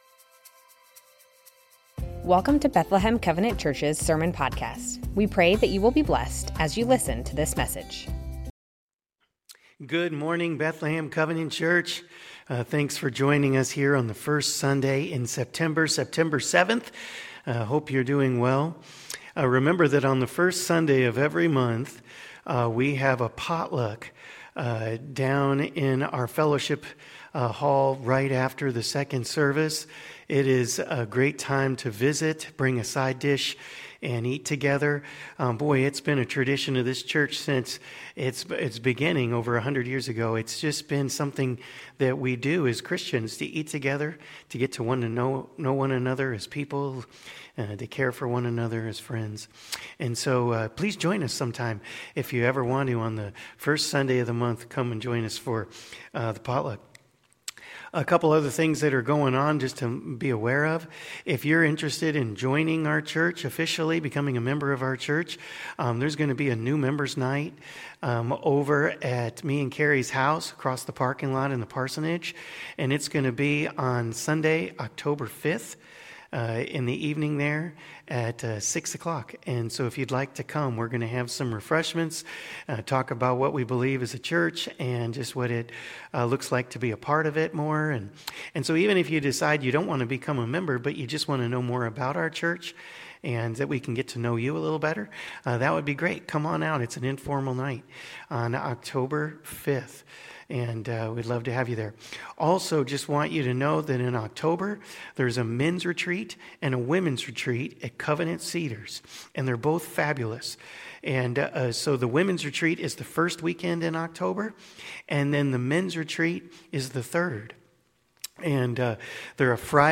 Bethlehem Covenant Church Sermons The Names of God - El Roi Sep 07 2025 | 00:34:01 1x 00:00 / 00:34:01 Subscribe Share Spotify RSS Feed Share Link Embed